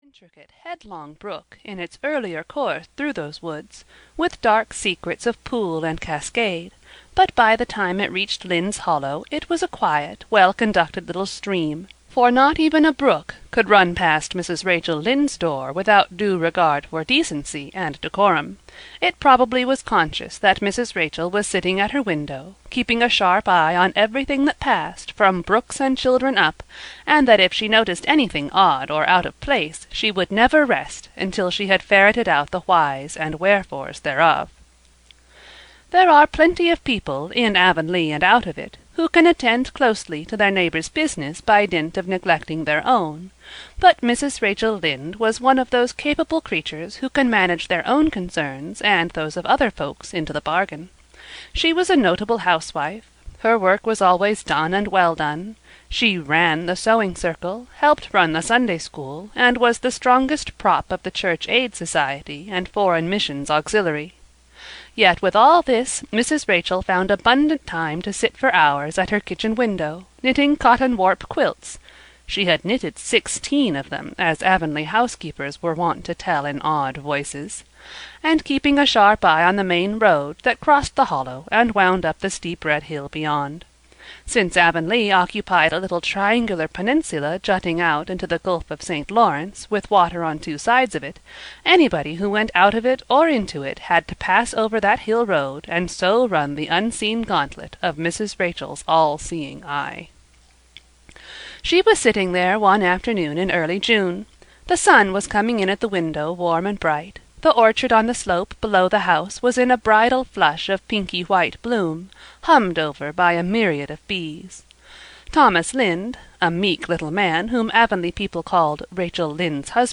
Anne of Green Gables (EN) audiokniha
Ukázka z knihy